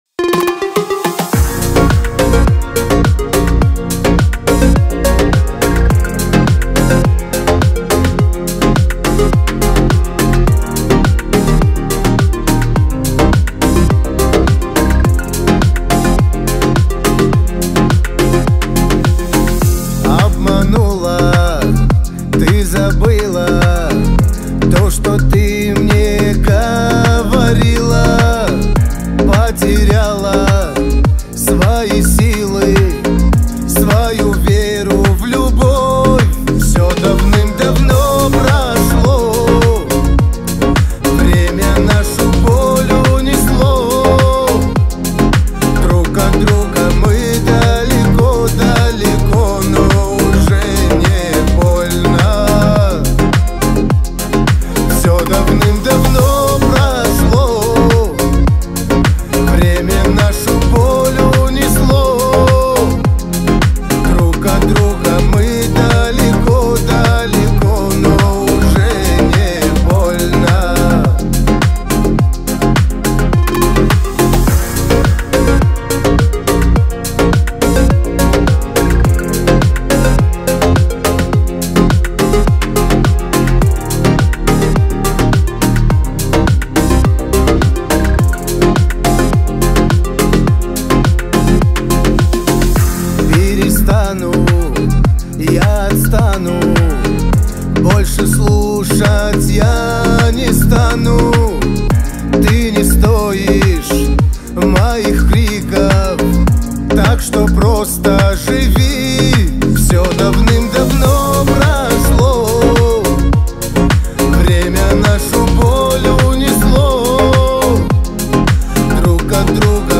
Русские песни
• Качество: 320 kbps, Stereo